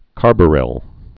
(kärbə-rĭl)